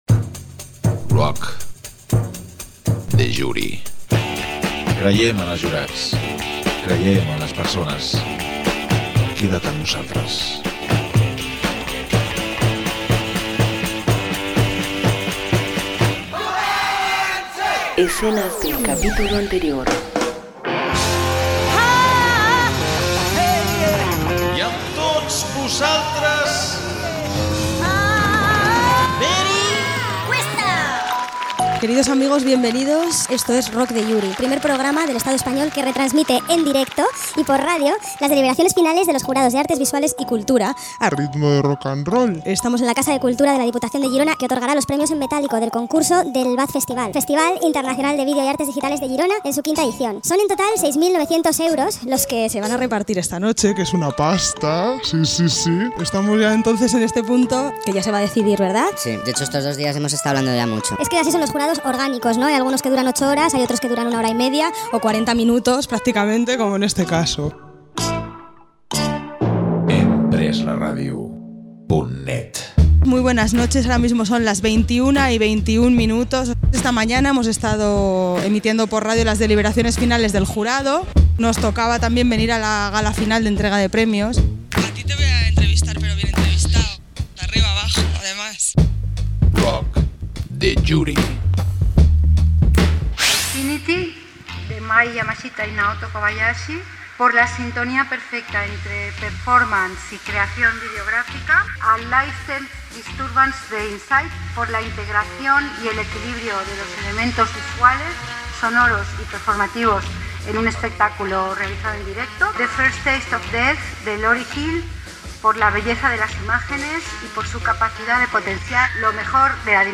Careta del programa, informació des de la Casa de Cultura de la Diputació de Girona sobre els premis del V Festival Internacional de Vídeos de Girona